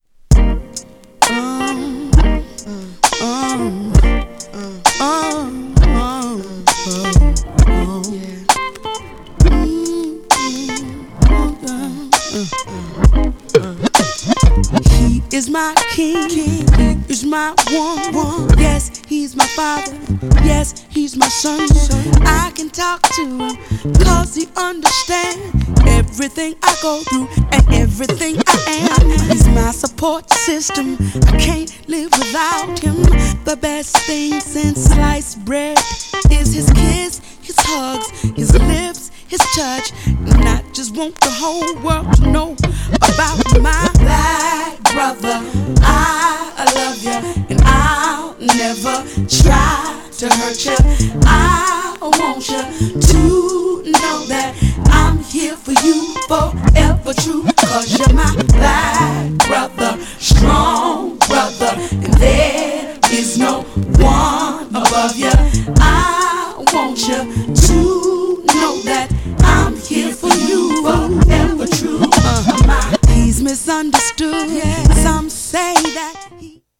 GENRE R&B
BPM 91〜95BPM